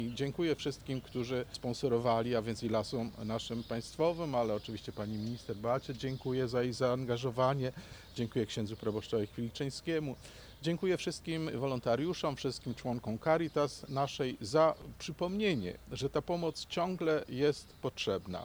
– Chciałbym podziękować wszystkim, którzy sponsorowali te meble – mówił abp Józef Kupny, metropolita wrocławski.